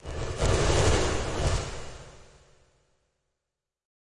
游戏音效 " FX190
描述：爆炸哔哔踢游戏gameound点击levelUp冒险哔哔sfx应用程序启动点击
Tag: 爆炸 单击 冒险 游戏 应用程序 点击的LevelUp 启动 gamesound 嘟嘟 SFX